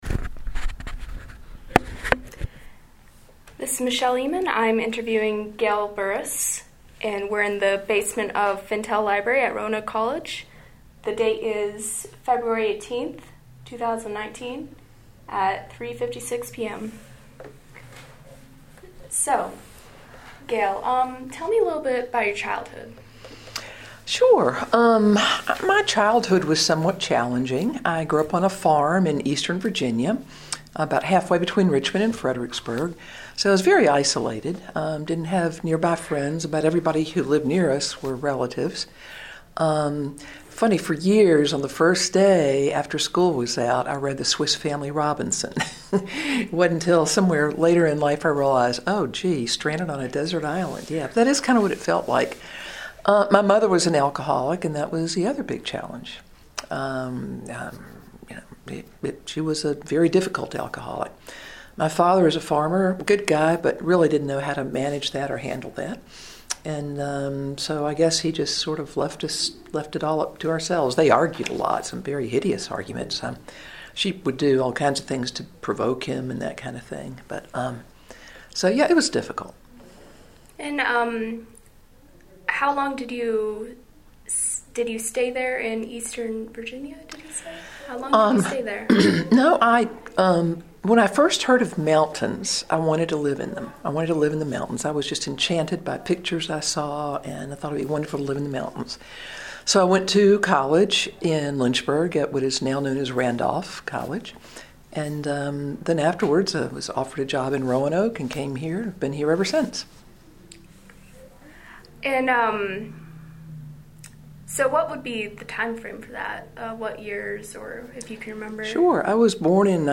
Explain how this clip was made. Location: Fintel Library, Roanoke College